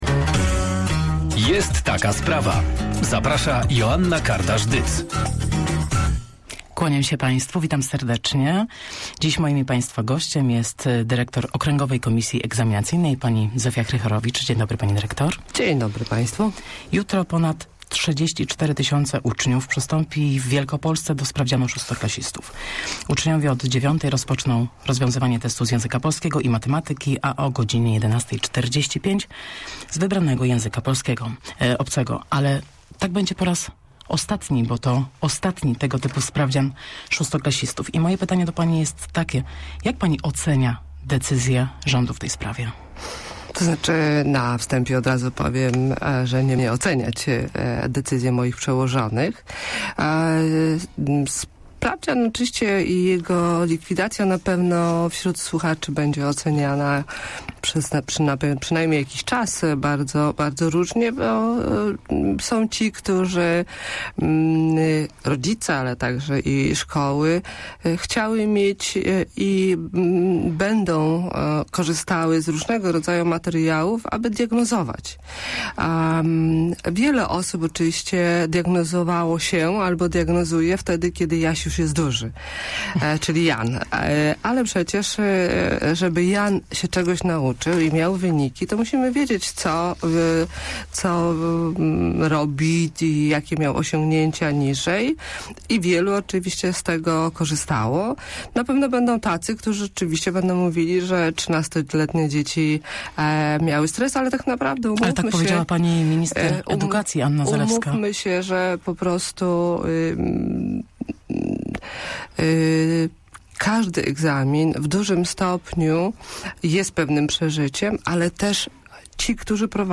(cała rozmowa poniżej)